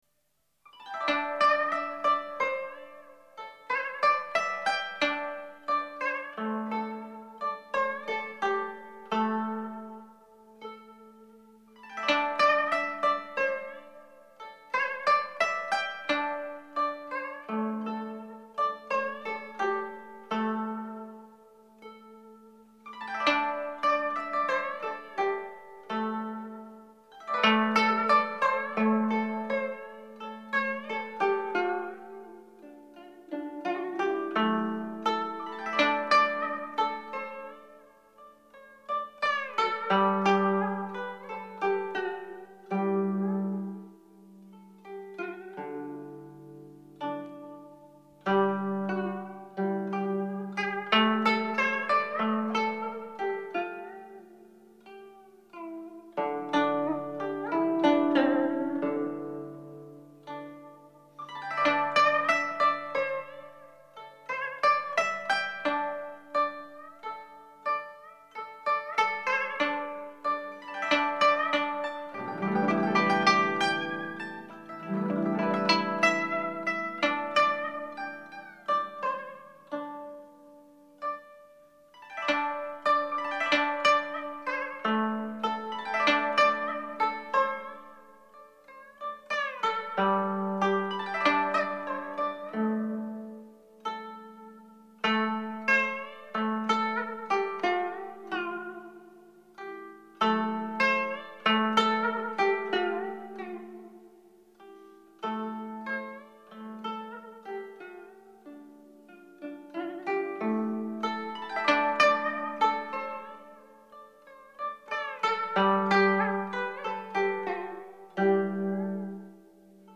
音乐类型: 民乐